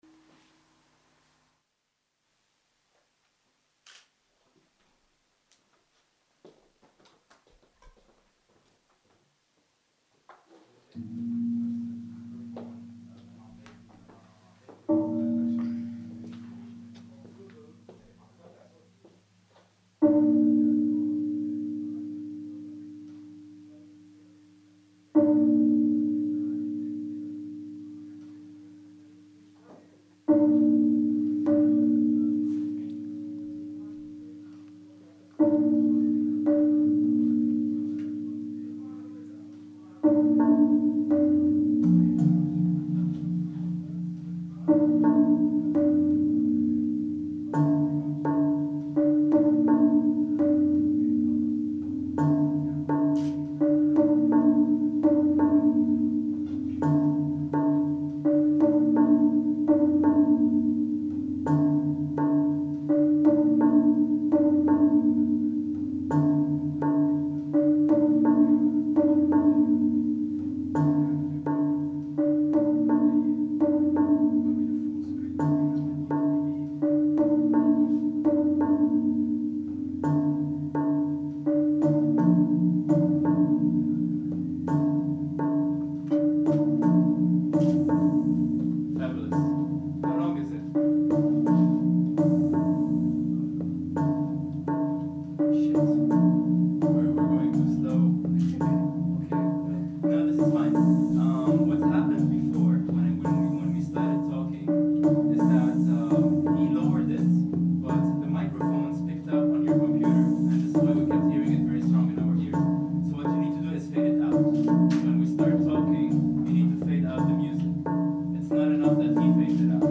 experimental musician